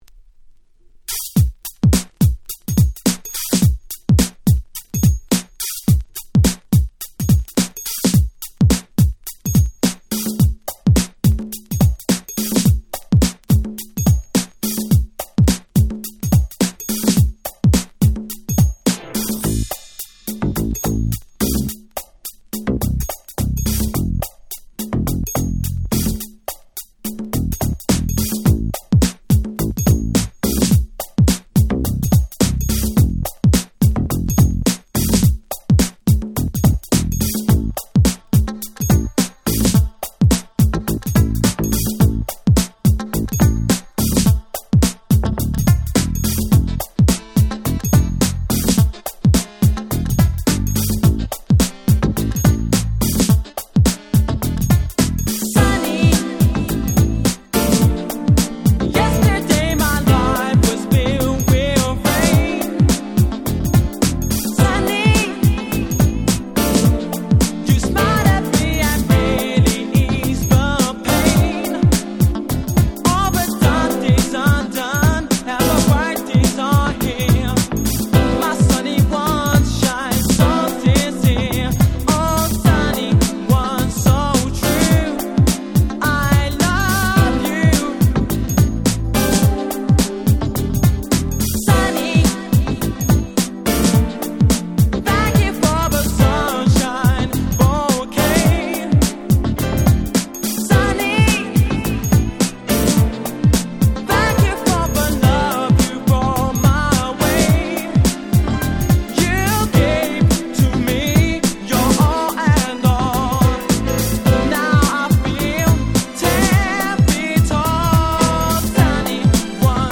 【Media】Vinyl 12'' Single
(試聴ファイルは別の盤から録音してございます。)